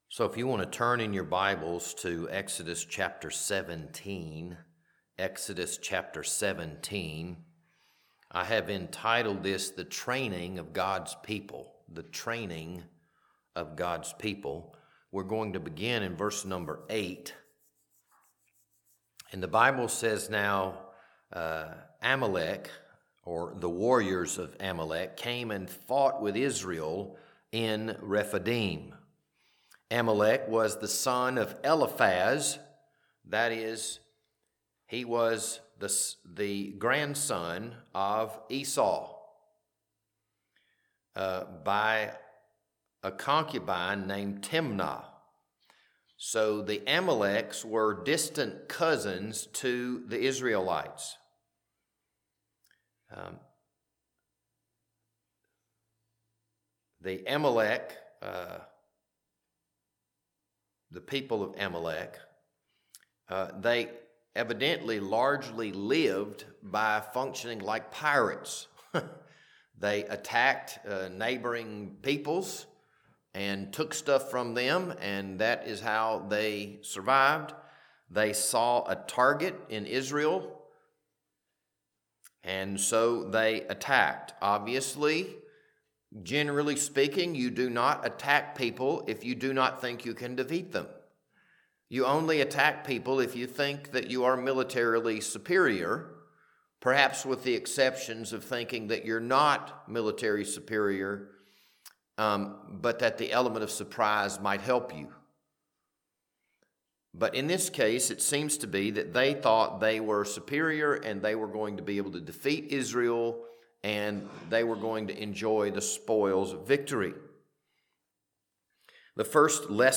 This Wednesday evening Bible study was recorded on April 8th, 2026.